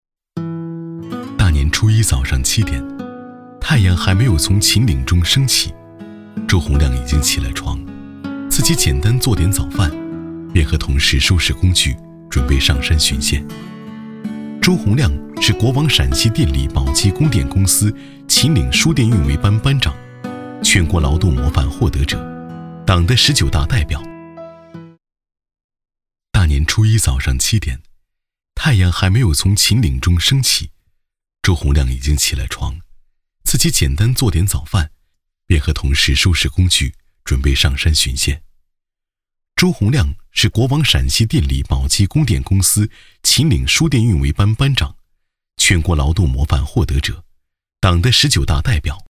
A男190号